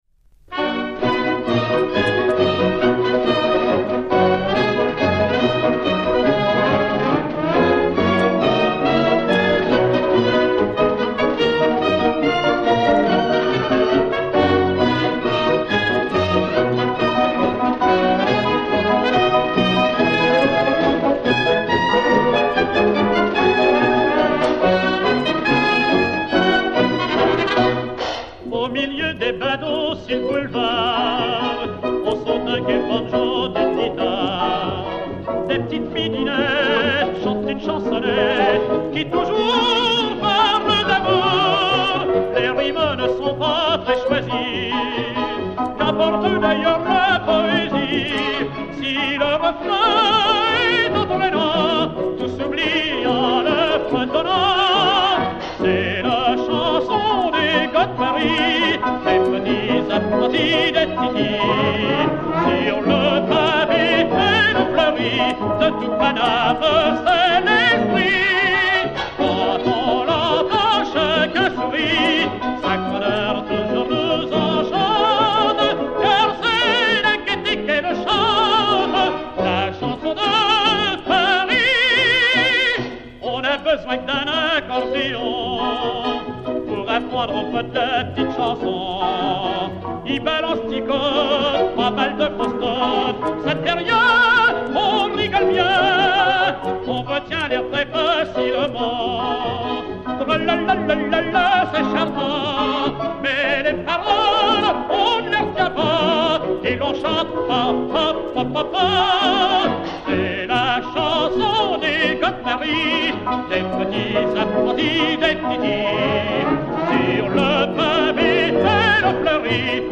marche chantée
ténor de l'Opéra